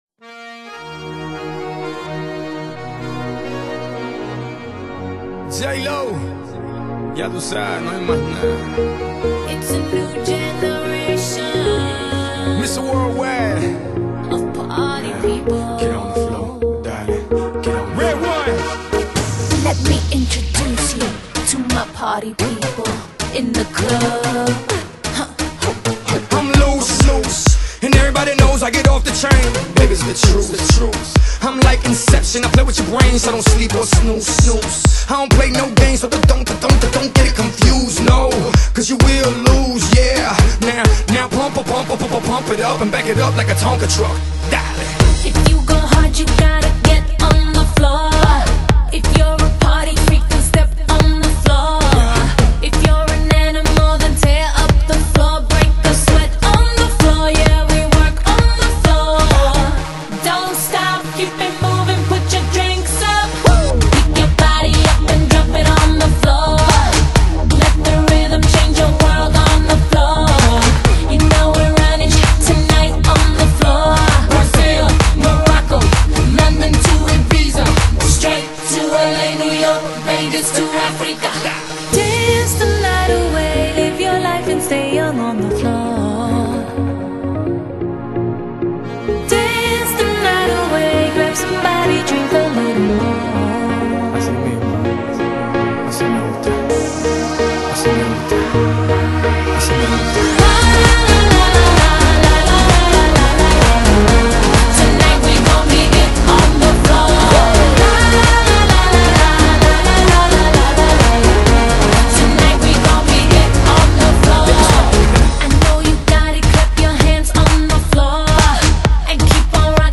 Pop, R&B, Dance | EAC Rip | Flac(Image) + Cue